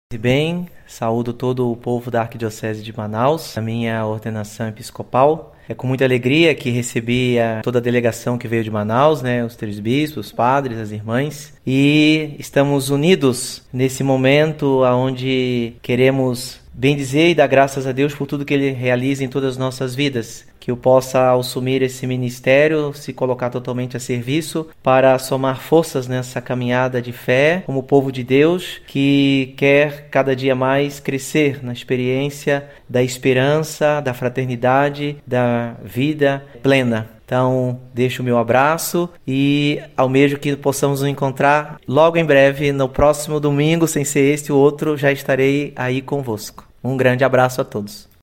Às vésperas da sua ordenação episcopal, o bispo eleito enviou uma saudação ao povo da Arquidiocese de Manaus, em que demonstra sua alegria por ter recebido em Santa Catarina, o arcebispo cardeal Leonardo Steiner, os bispos auxiliares, dom zenildo lima e dom hudson ribeiro, padres e religiosas para acompanharem sua cerimônia de ordenação.